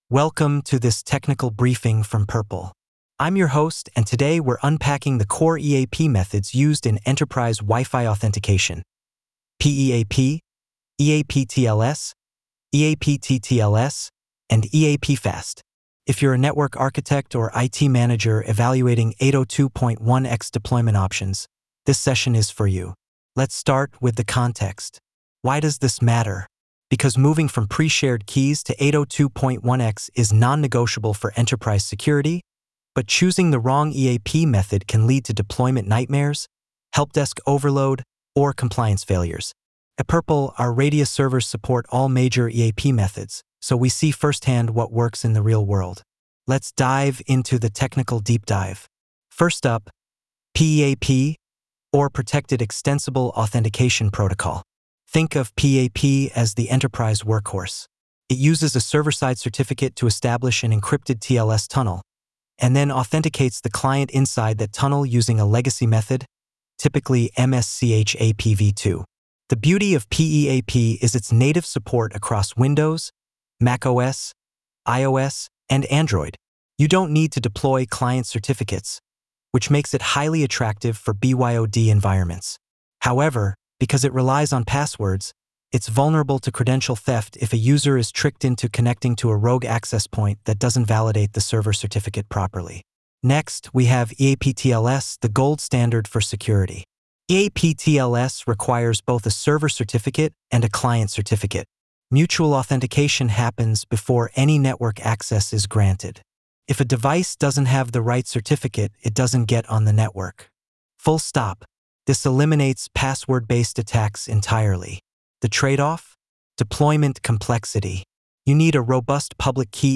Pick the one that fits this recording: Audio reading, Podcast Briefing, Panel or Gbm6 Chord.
Podcast Briefing